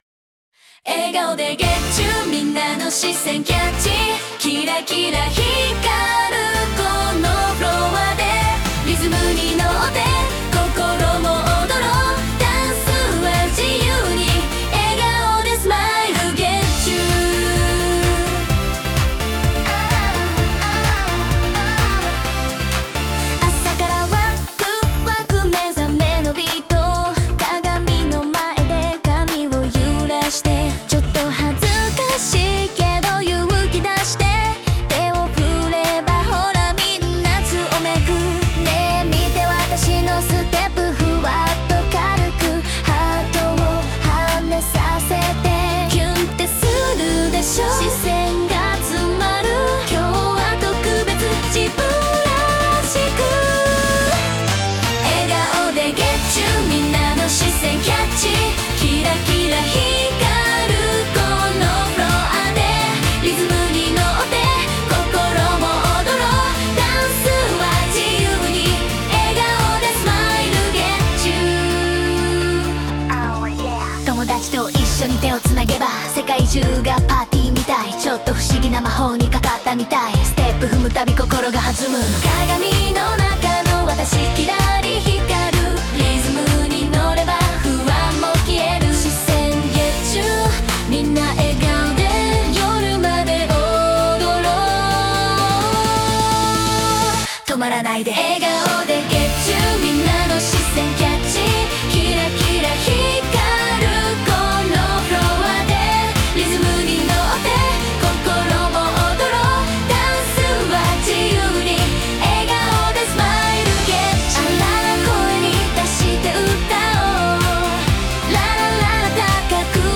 明るい気持ちになれるハッピーソング！